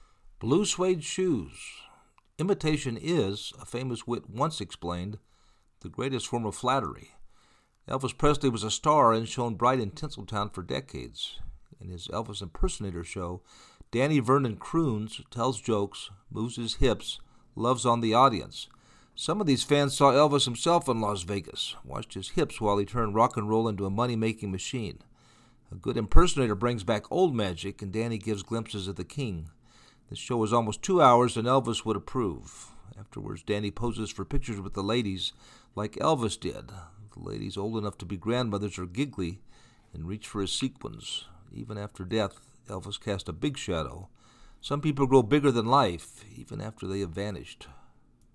In his Elvis impersonator show
croons